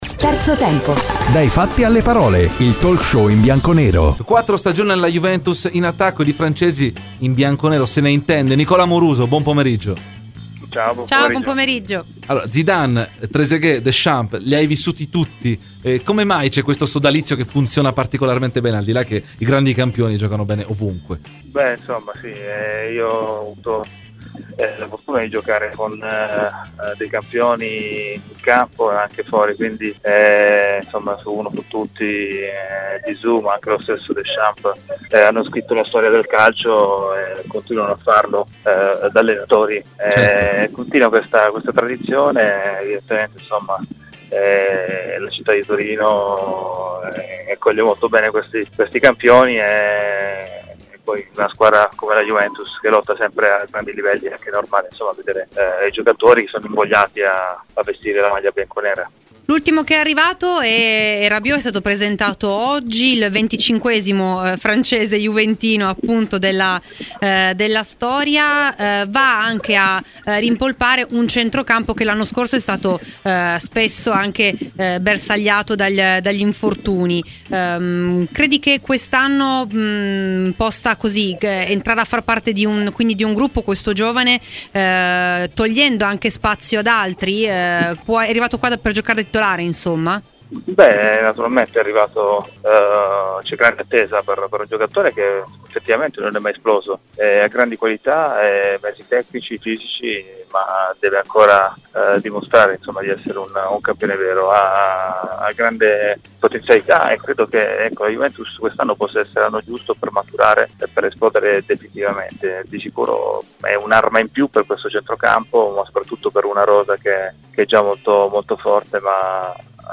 Nicola Amoruso ai microfoni di "Terzo tempo" su Radio Bianconera © registrazione di Radio Bianconera